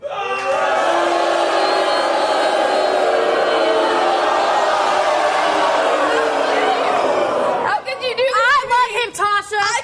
Crowd booing